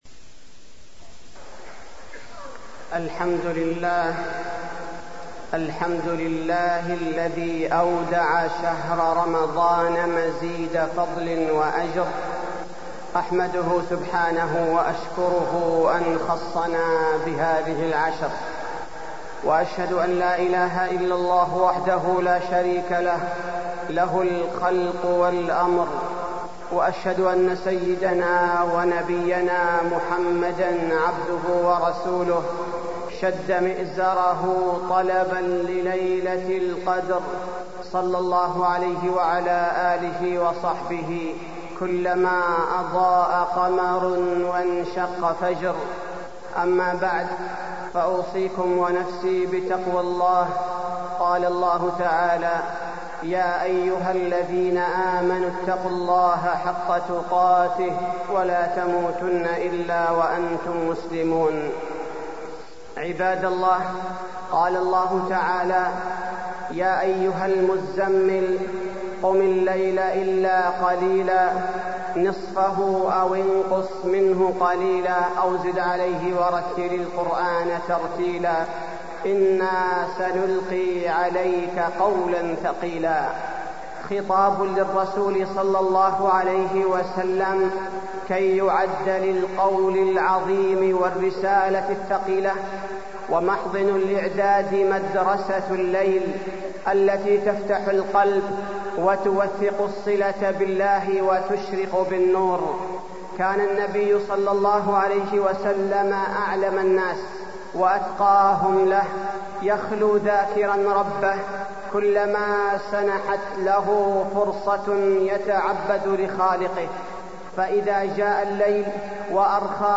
تاريخ النشر ١٧ رمضان ١٤٢٣ هـ المكان: المسجد النبوي الشيخ: فضيلة الشيخ عبدالباري الثبيتي فضيلة الشيخ عبدالباري الثبيتي قيام الليل